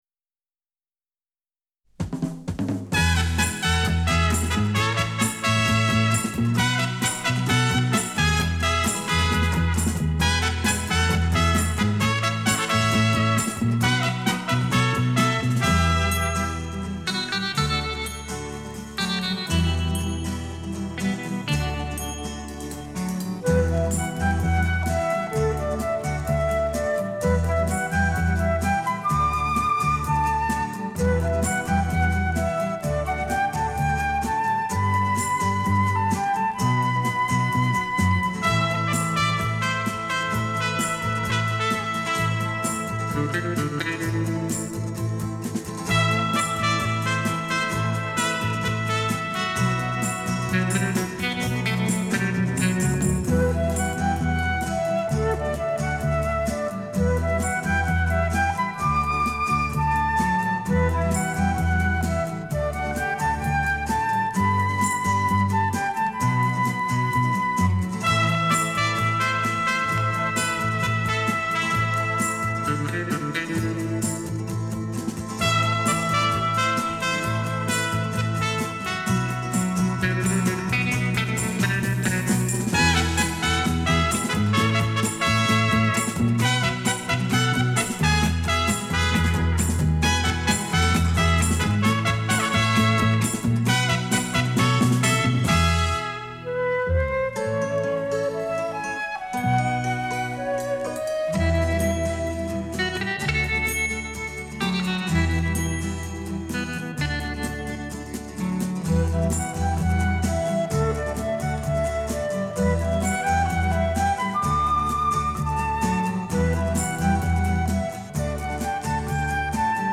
Запись, которая поднимает настроение.